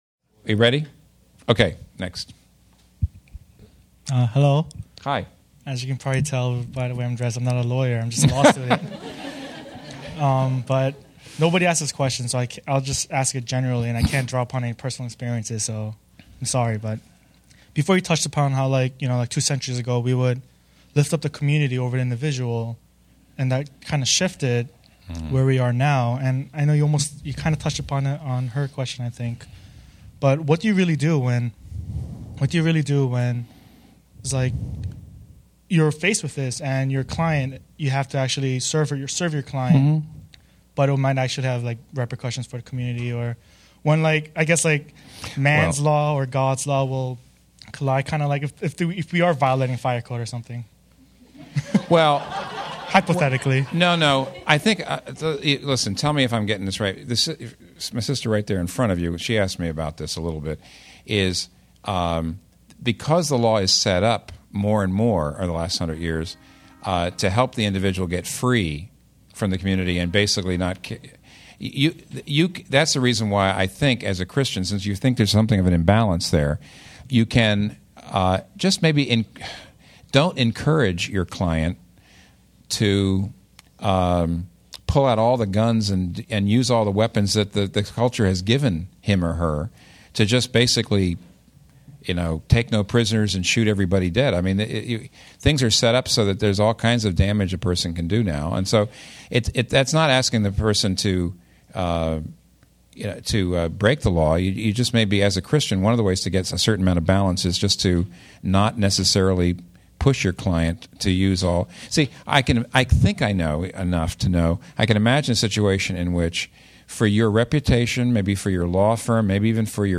Timothy Keller answers questions in the Q & A follow up to his discussion on the importance of the legal profession.